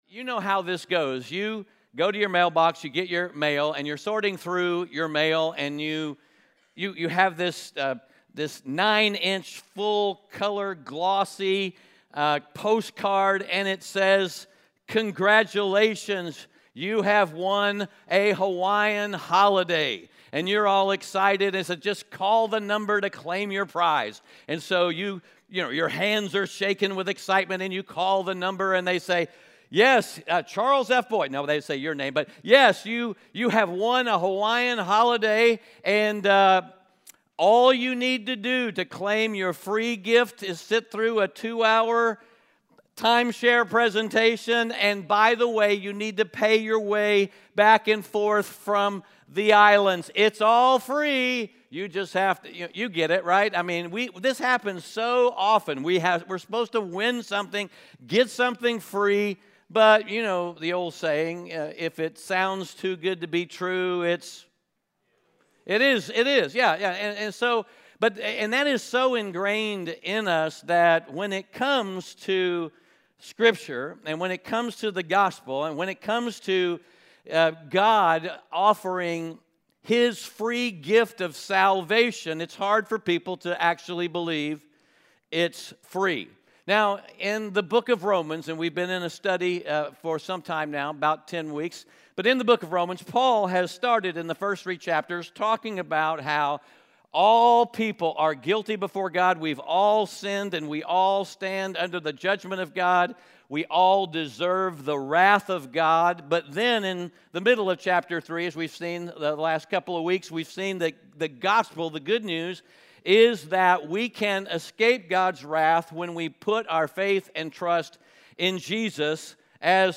Romans 4:1-12 Audio Sermon Notes (PDF) Onscreen Notes Ask a Question *We are a church located in Greenville, South Carolina.